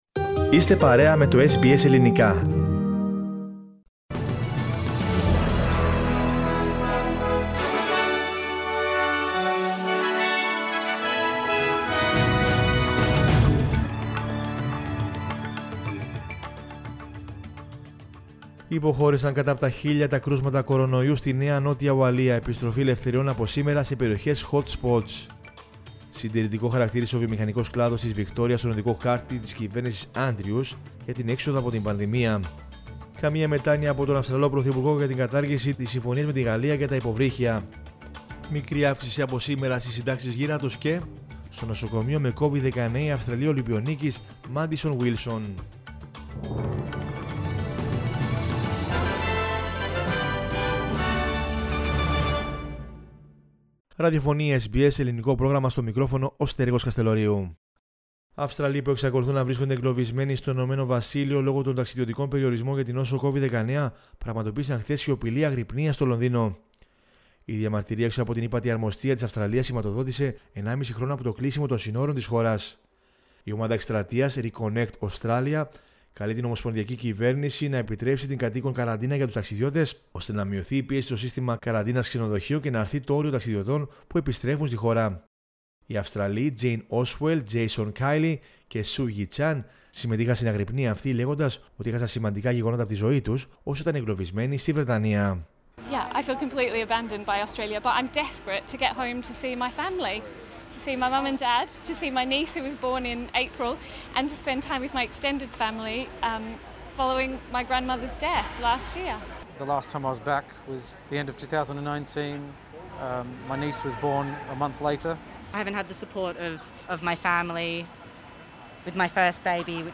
News in Greek from Australia, Greece, Cyprus and the world is the news bulletin of Monday 20 September 2021.